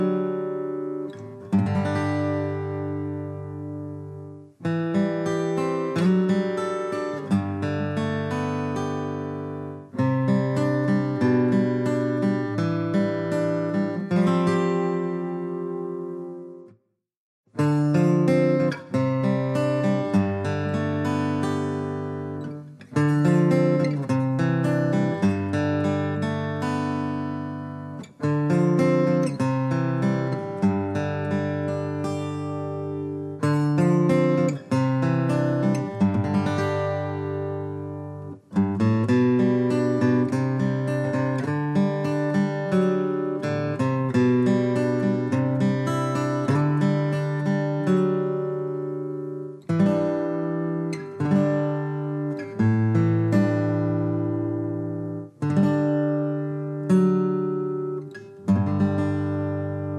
Musicals